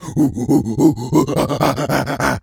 pgs/Assets/Audio/Animal_Impersonations/gorilla_chatter_02.wav at master
gorilla_chatter_02.wav